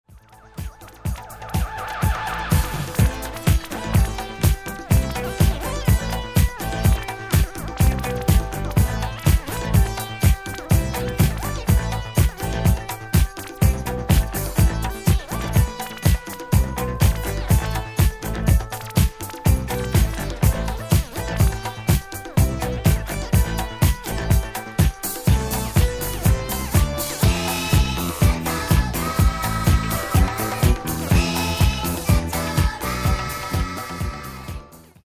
Genere:   Disco | Funk | Soul